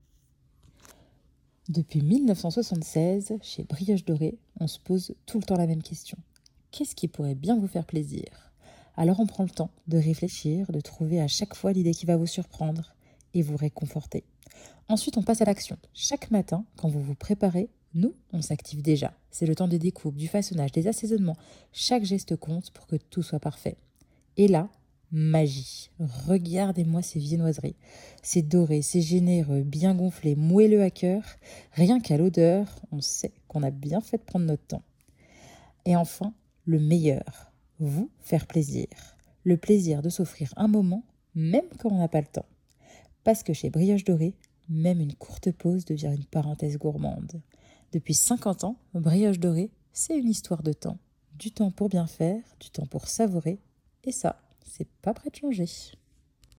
VO Brioche dorée
20 - 35 ans